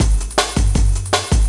06 LOOP07 -R.wav